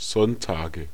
Ääntäminen
Ääntäminen Tuntematon aksentti: IPA: /ˈzɔn.taː.ɡə/ Haettu sana löytyi näillä lähdekielillä: saksa Käännöksiä ei löytynyt valitulle kohdekielelle. Sonntage on sanan Sonntag monikko.